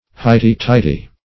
Highty-tighty \High"ty-tigh"ty\, a.